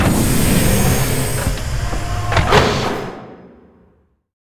taxiopen.ogg